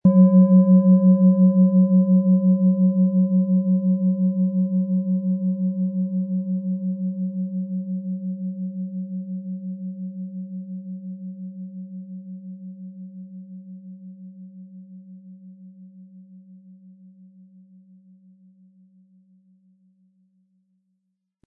OM Ton
Antike Klangschalen – ein Klangraum für Weite, Klarheit und Verbundenheit
• Zwei Frequenzen – eine tiefe Wirkung: Die Verbindung aus Biorhythmus Geist und Om-Ton vereint mentale Klarheit mit spiritueller Weite
• Warmer, weiter Klang: Unterstützt dich beim Loslassen, Zentrieren und in Momenten der Selbstbegegnung